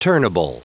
Prononciation du mot turnable en anglais (fichier audio)
Prononciation du mot : turnable